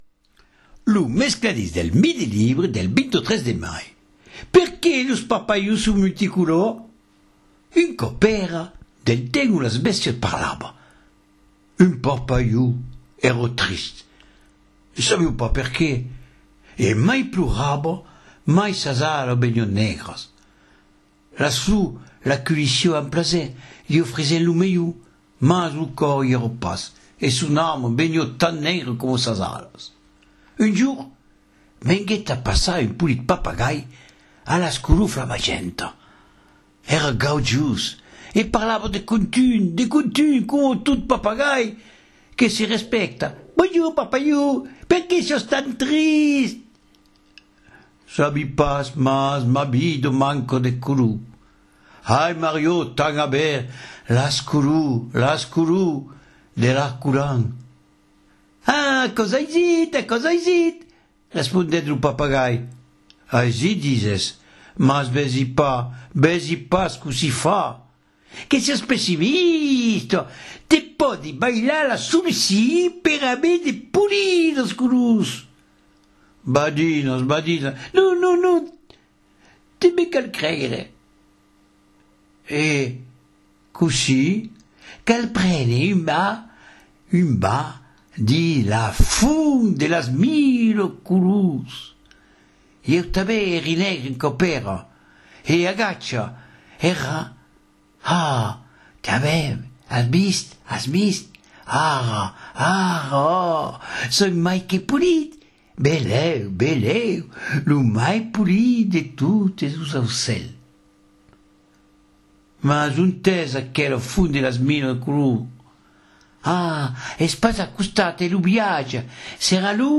Conte : Perqué los parpalhons son multicolòrs ?